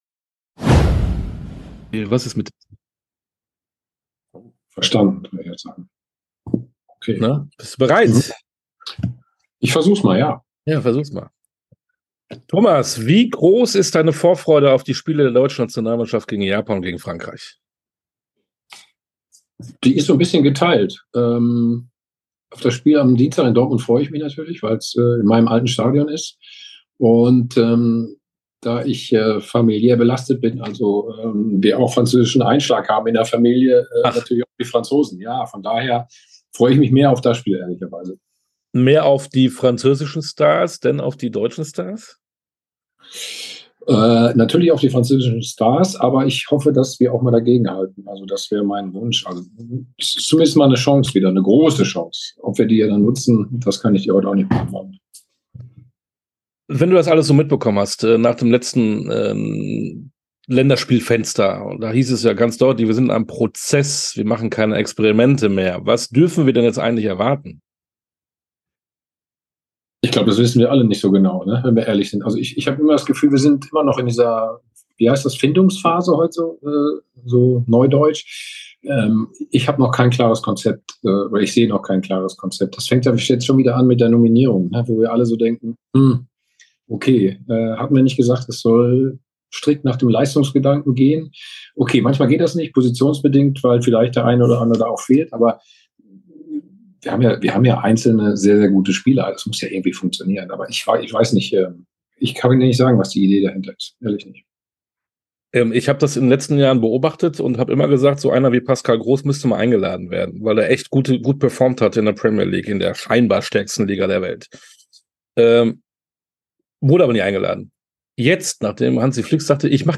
Sportstunde - Thomas Helmer ~ Sportstunde - Interviews in voller Länge Podcast